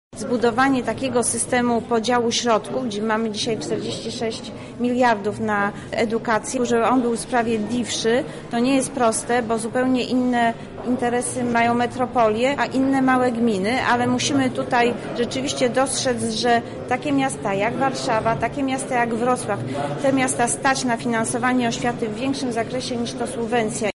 Wśród istotnych zagadnień były między innymi finanse, o czym mówi wiceminister edukacji Marzena Machałek: